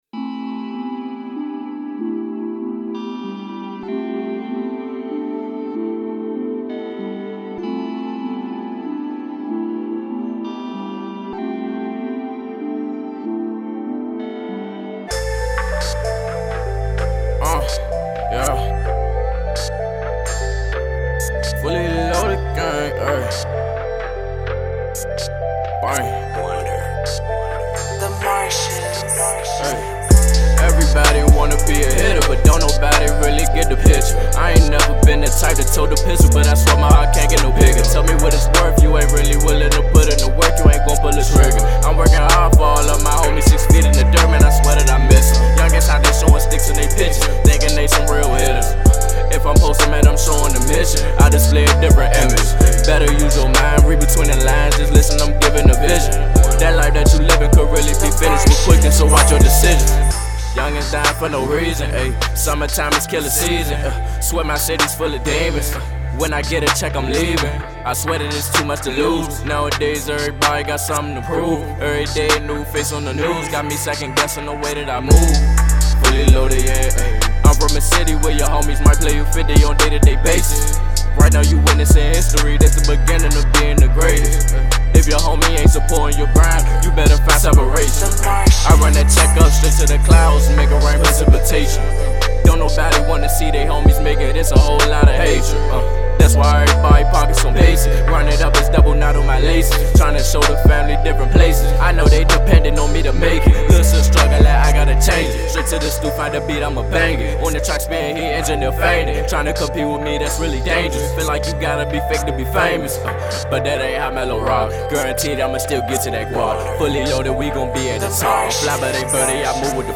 An Indie Hip Hop Artist from Washington, DC.